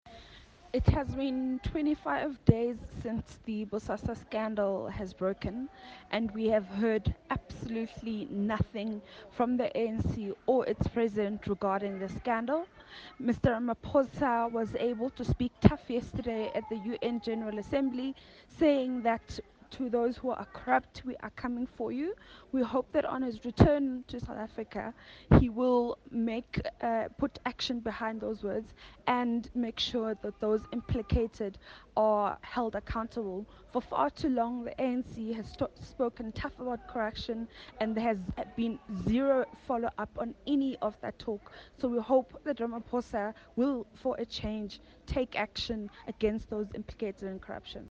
soundbite in English by DA Team One SA Spokesperson on Corruption, Phumzile Van Damme MP.